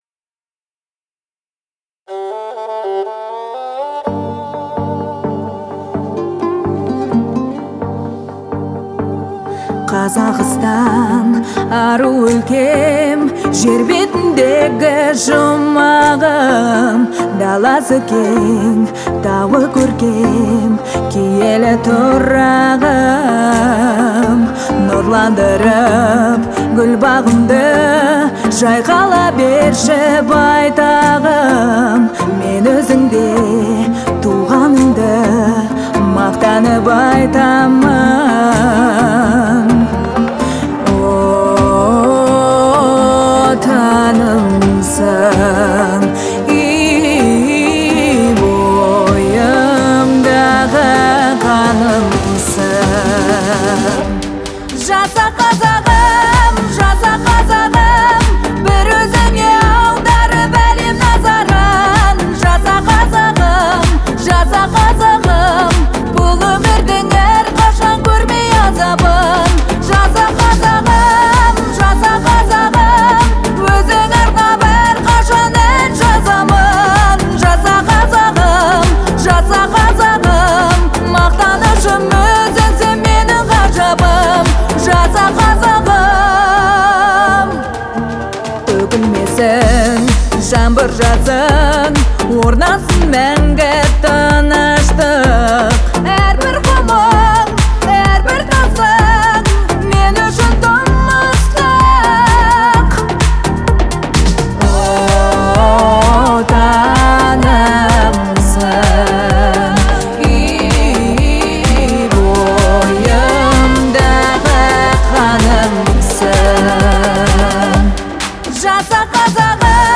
это яркая и вдохновляющая песня в жанре поп
но и эмоциональной подачей